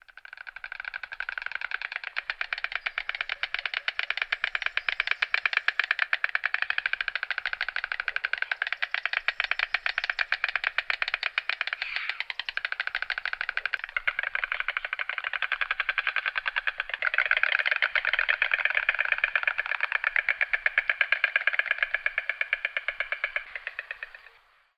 Stork sound - Eğitim Materyalleri - Slaytyerim Slaytlar
stork-sound